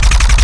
GUNAuto_RPU1 B Loop_03_SFRMS_SCIWPNS.wav